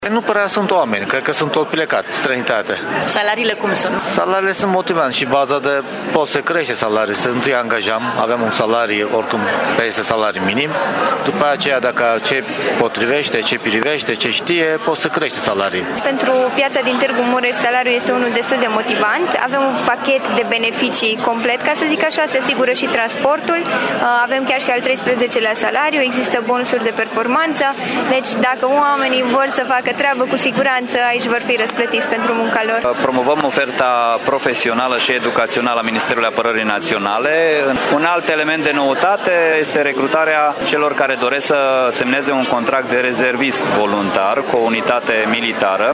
Și angajatorii găsesc greu oameni pentru locurile vacante, deși oferă pachete salariale motivante, spun ei: